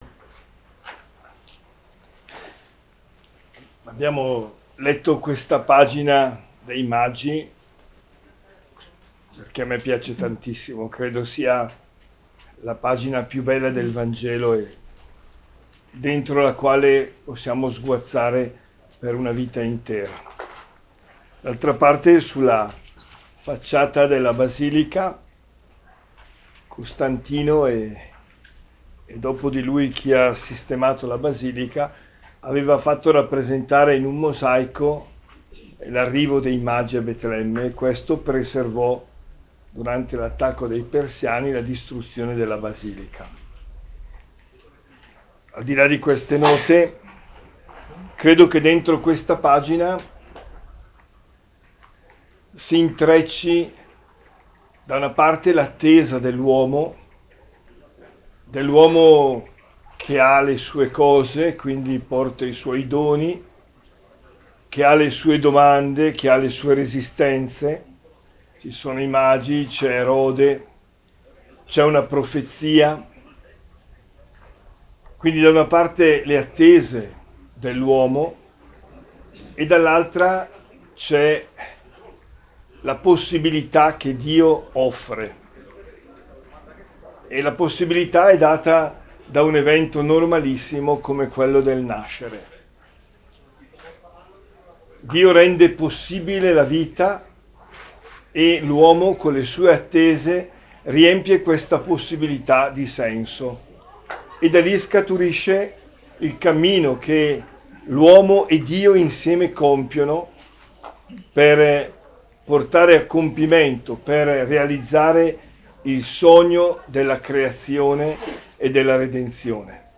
OMELIA DELLA CELEBRAZIONE A BETLEMME